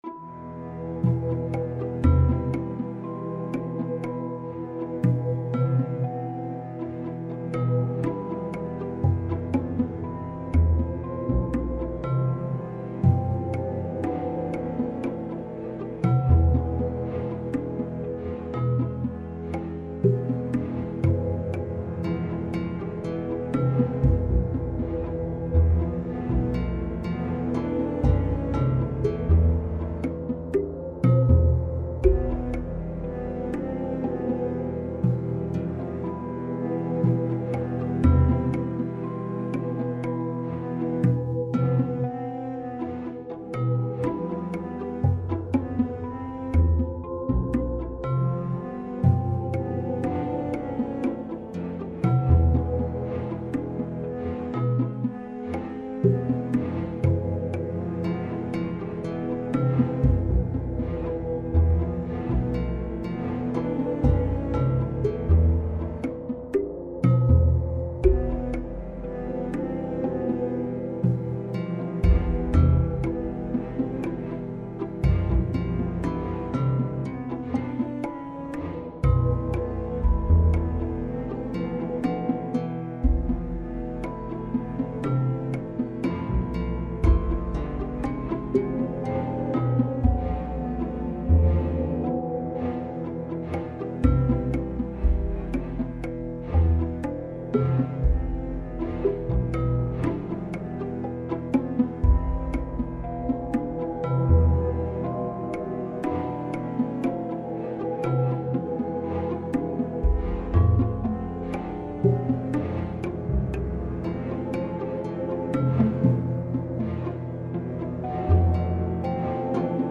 Night Music, a digital composition working with expanding sonata process into a very slow time-scale; this is what corresponds to the ‘exposition’ of sonata process…